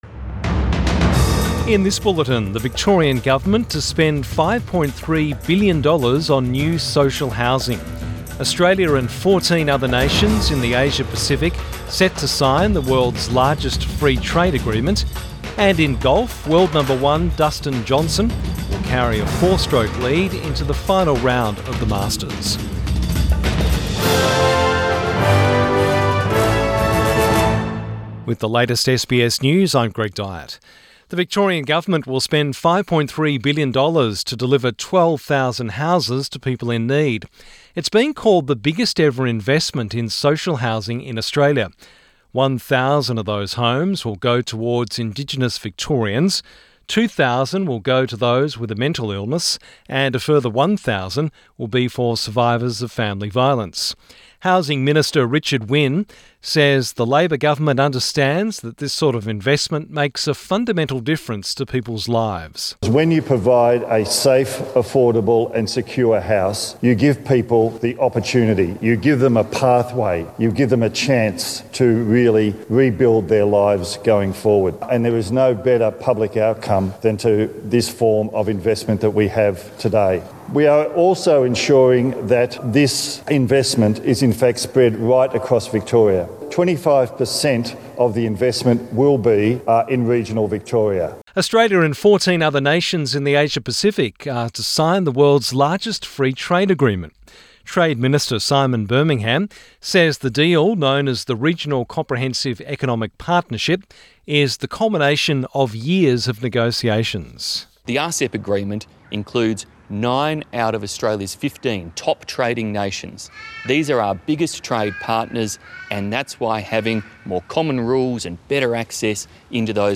PM bulletin 15 November 2020